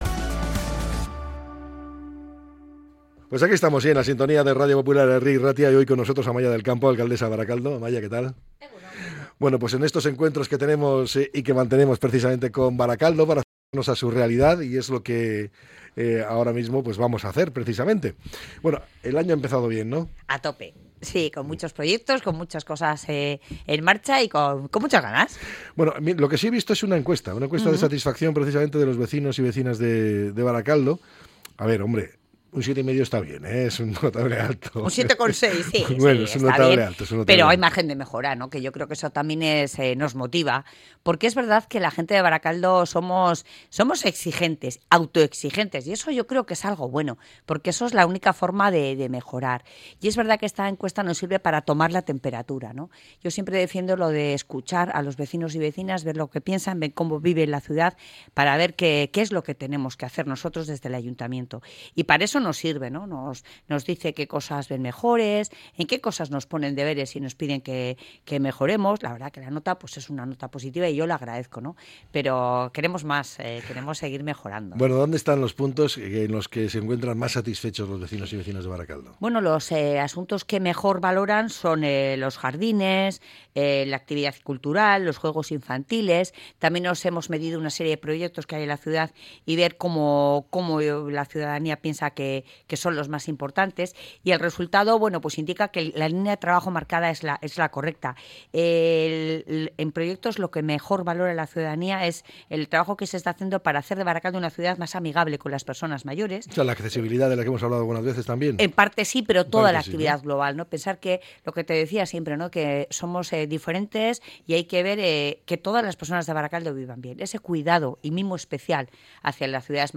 La alcaldesa de Barakaldo ha valorado los resultados de la encuesta de satisfacción realizada a la ciudadanía